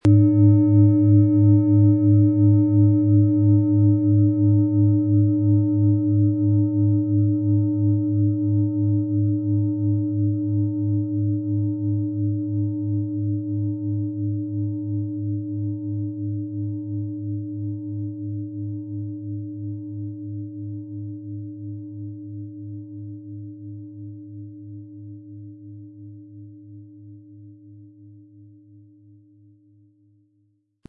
• Mittlerer Ton: Saturn
Um den Original-Klang genau dieser Schale zu hören, lassen Sie bitte den hinterlegten Sound abspielen.
Spielen Sie die Neptun mit dem beigelegten Klöppel sanft an, sie wird es Ihnen mit wohltuenden Klängen danken.
PlanetentöneNeptun & Saturn & Merkur (Höchster Ton)
MaterialBronze